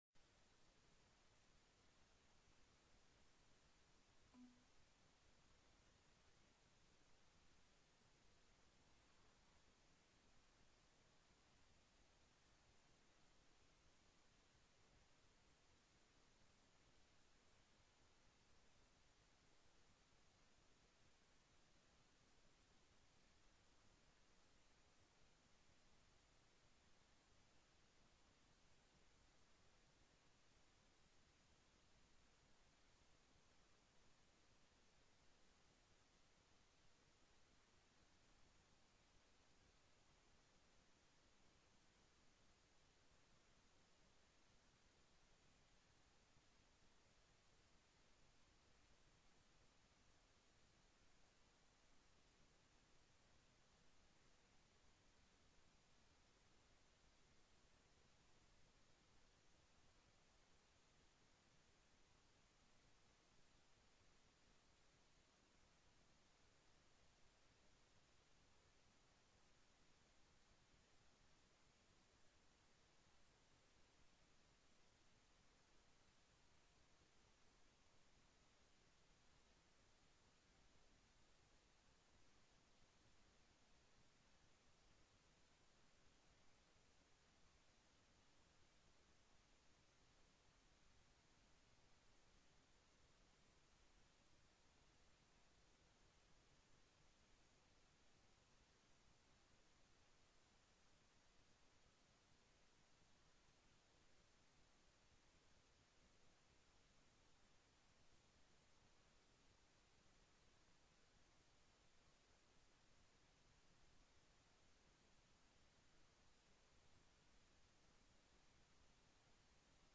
+ teleconferenced
+= SB 28 LIMIT OVERTIME FOR REGISTERED NURSES TELECONFERENCED
+ Presentation: Sound Families Initiative TELECONFERENCED